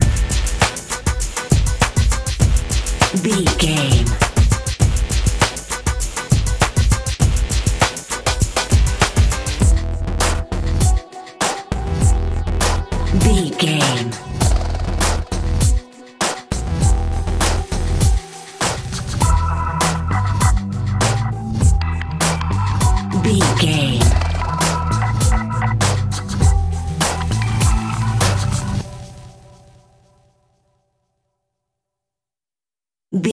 Aeolian/Minor
synthesiser
drum machine
hip hop
Funk
neo soul
acid jazz
confident
energetic
bouncy
Triumphant
funky